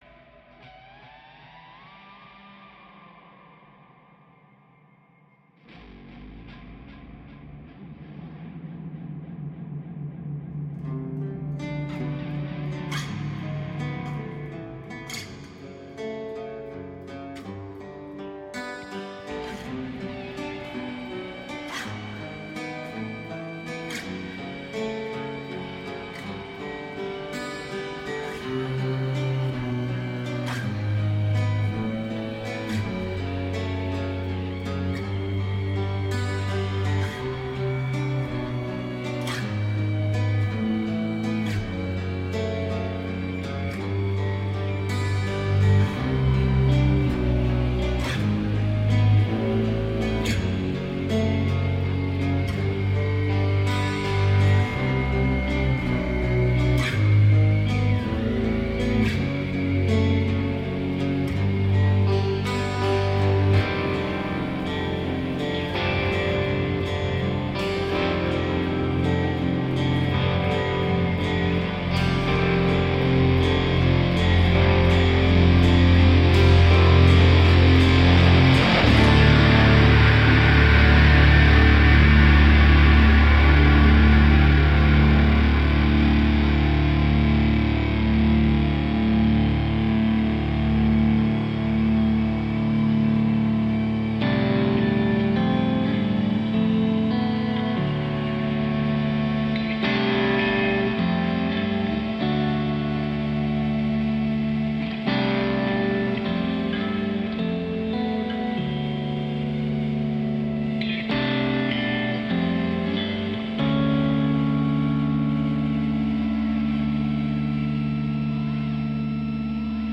Experimetal - instrumental/metal/jazz.
Tagged as: Hard Rock, Experimental, Instrumental